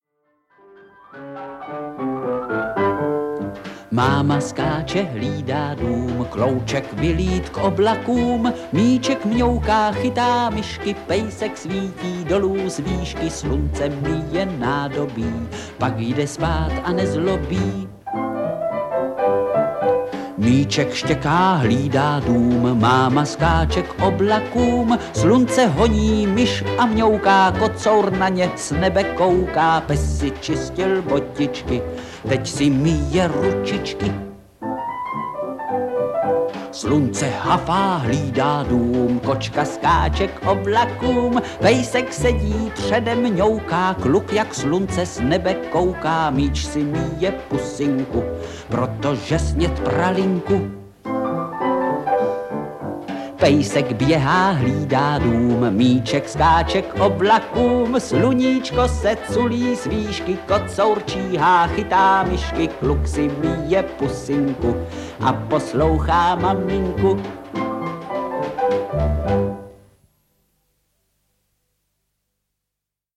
Audiokniha
Čte: Vlastimil Brodský